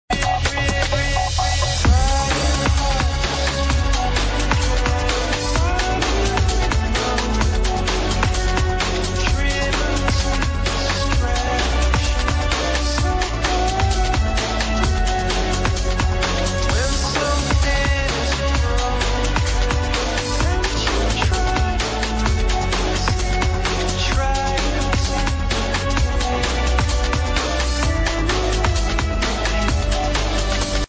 wonderful epic breaks tune